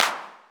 80-s-clap(2).wav